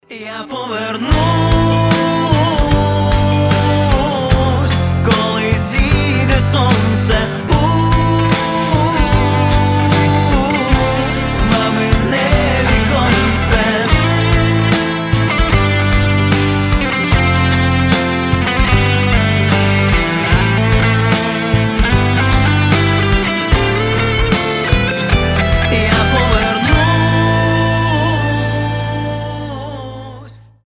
гитара
грустные